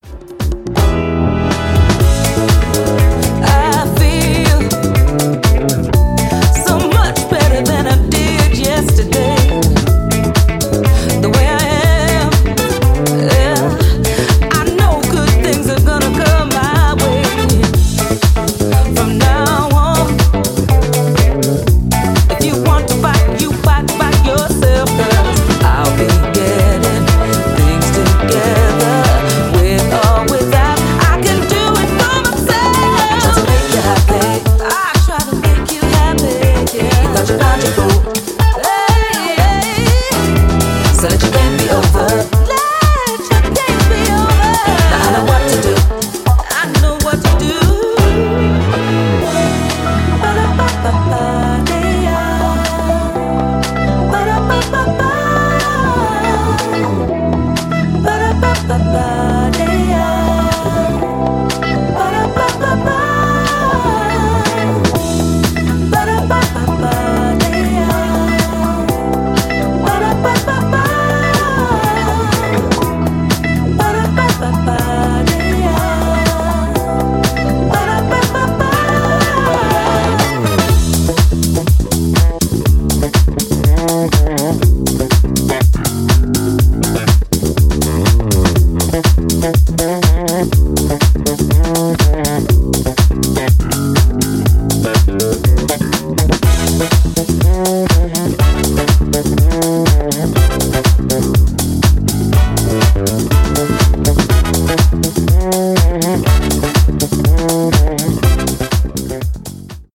supplier of essential dance music
Disco House